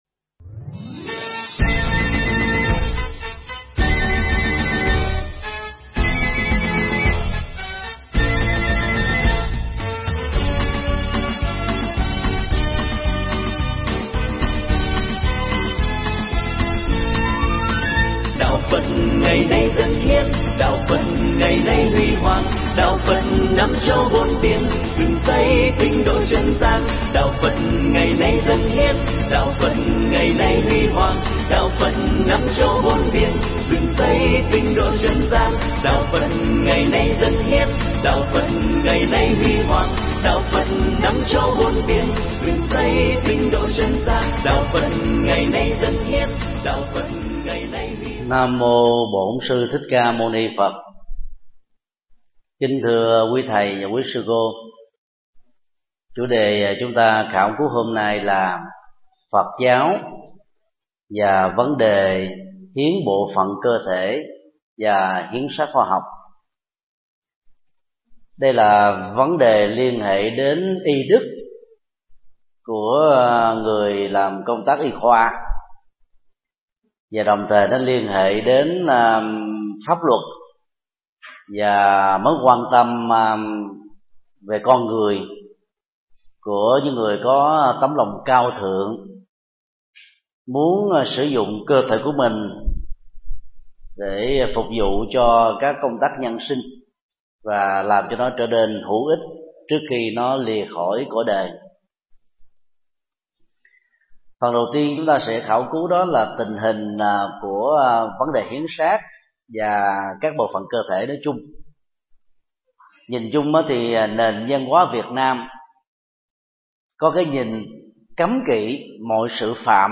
Mp3 Pháp thoại Phật giáo ứng dụng 07: Phật giáo và hiến nội tạng, hiến xác – Thích Nhật Từ giảng tại học viện PGVN tại TP. HCM, ngày 22 tháng 11 năm 2011